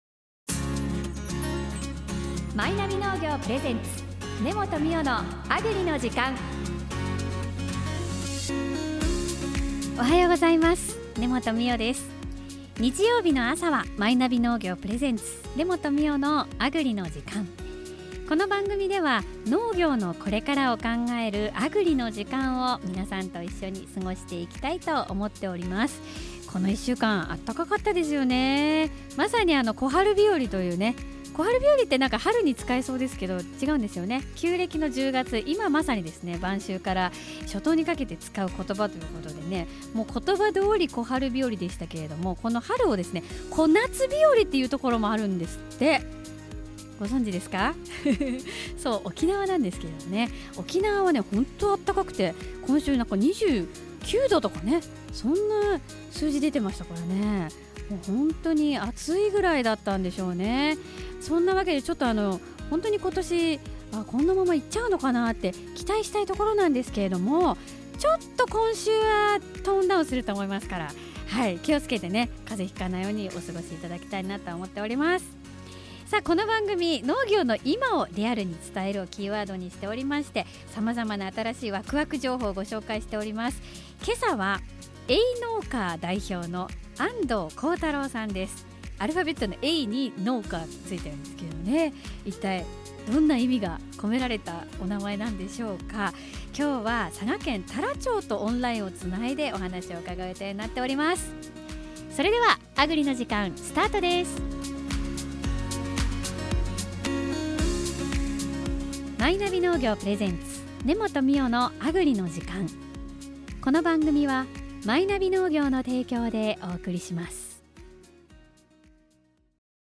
自然薯農家さん訪問のために唐津にいらっしゃるところを、お電話でご出演いただきました。